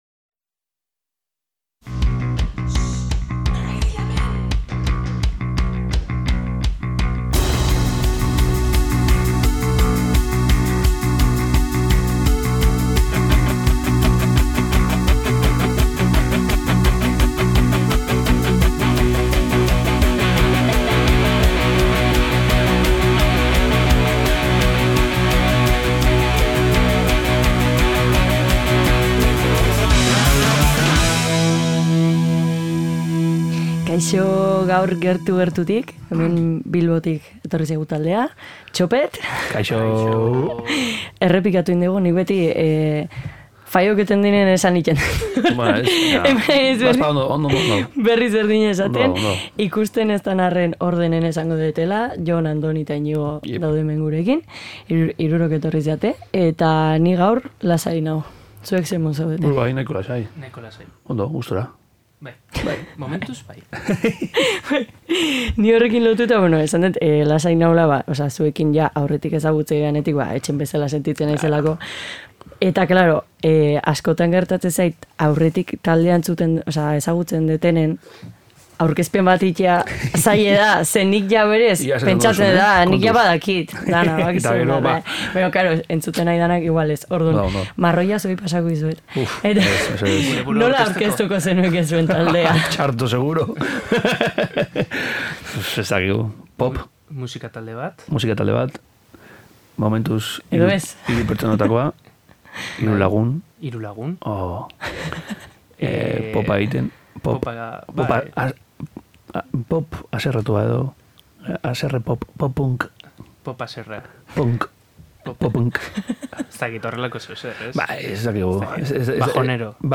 Hizketaldi politaz gain, gomendio musikal benetan bereziak bota dizkigute gainera. Eta hori gutxi balitz, demo berri bat aurkeztu digute!